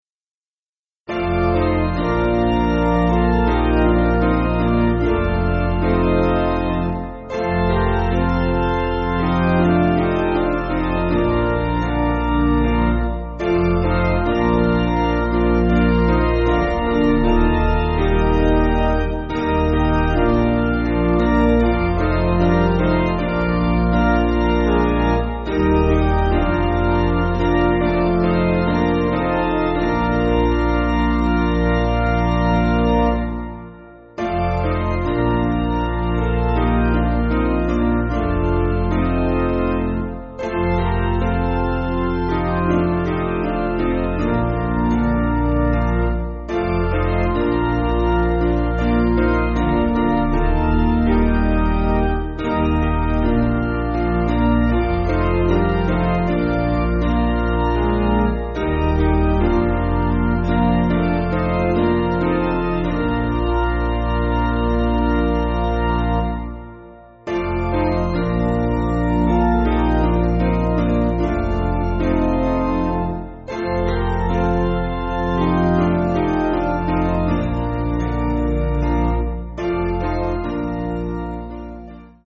Basic Piano & Organ
(CM)   5/Bb
4/4 Time